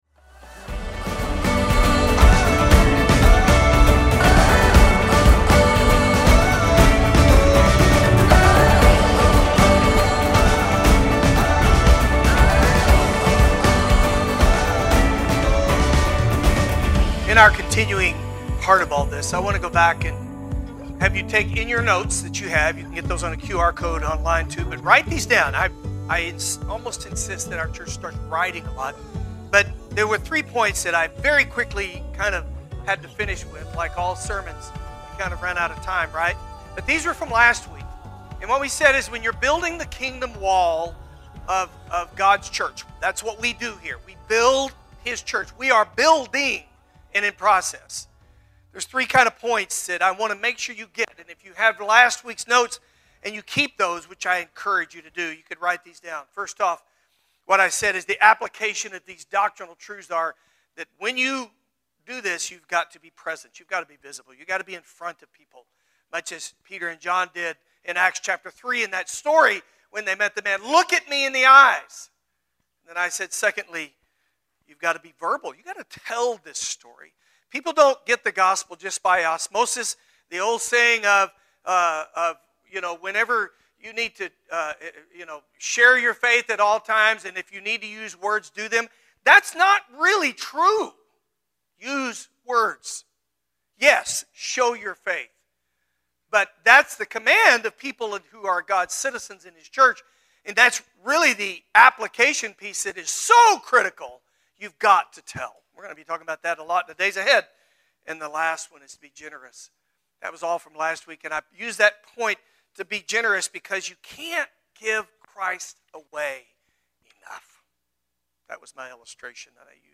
Download the sermon notes (adult version)